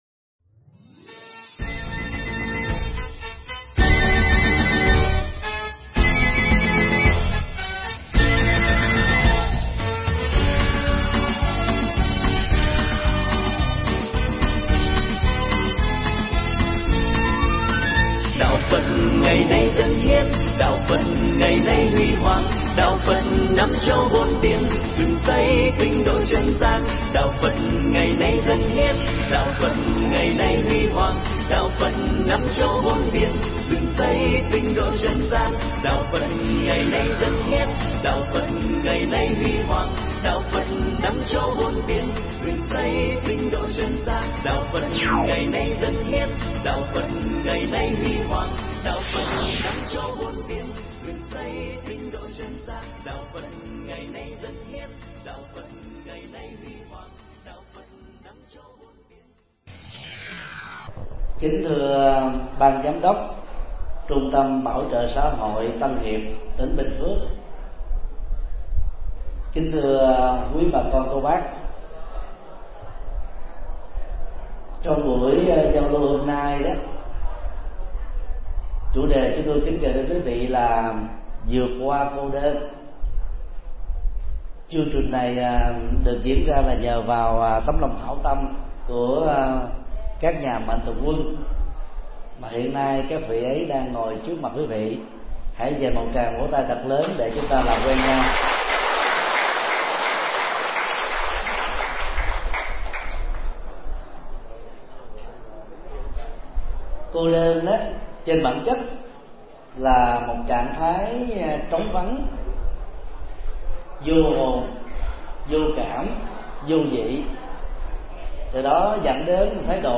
Bài giảng Vượt Qua Cô Đơn được thầy Thích Nhật Từ giảng tại Trung tâm Bảo trợ Xã hội Tân Hiệp, Bình Phước, ngày 08 tháng 06 năm 2013